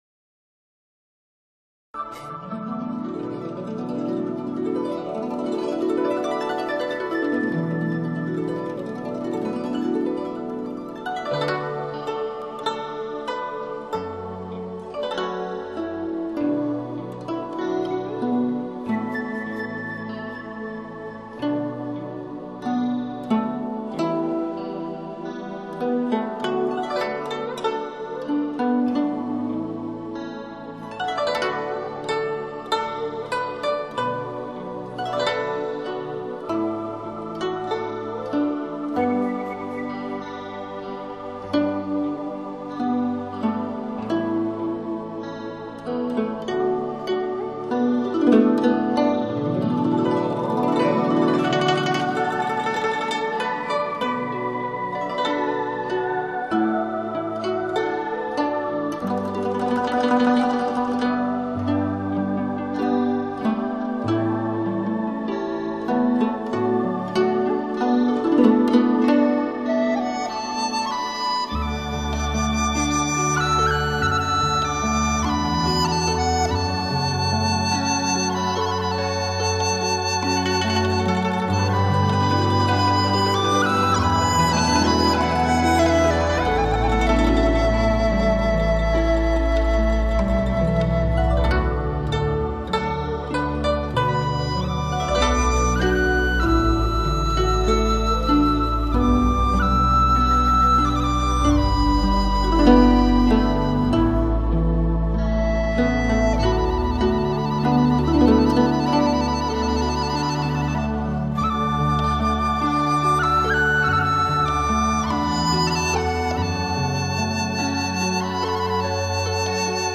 这是一套专为都市大众编配设计的心灵平衡乐。
编配人员独具匠心，巧妙的将中西音乐精品完美融合。
音乐个性鲜明，两种音乐相映成辉，带给听者宁静、淡泊的自然感受。